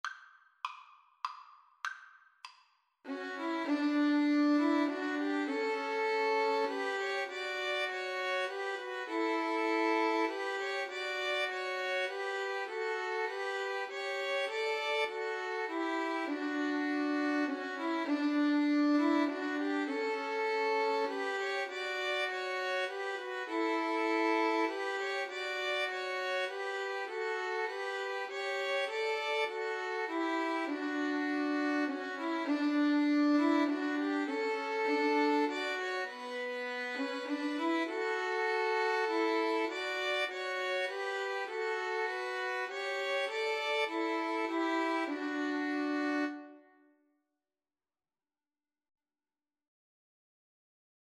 Violin 1Violin 2Violin 3
3/4 (View more 3/4 Music)